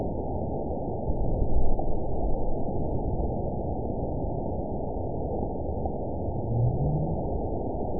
event 922874 date 04/30/25 time 00:56:21 GMT (1 month, 2 weeks ago) score 9.41 location TSS-AB02 detected by nrw target species NRW annotations +NRW Spectrogram: Frequency (kHz) vs. Time (s) audio not available .wav